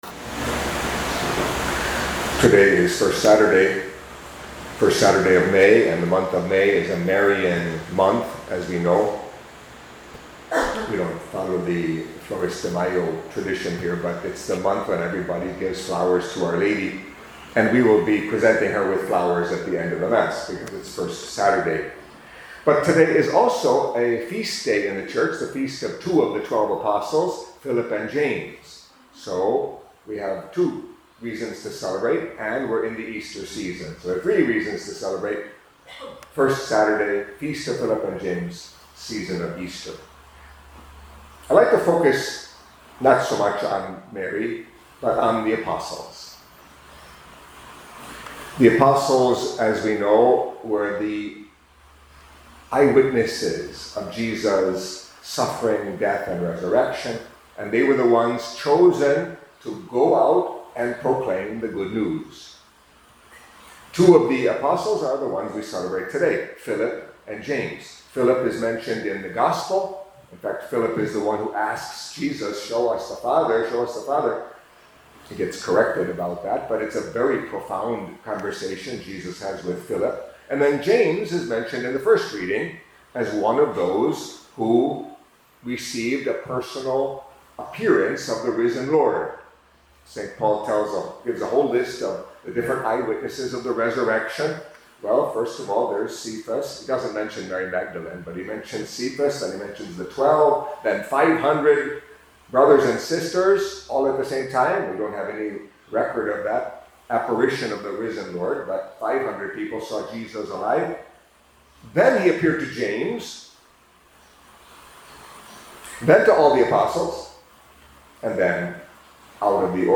Catholic Mass homily for Feast of Saints Philip and James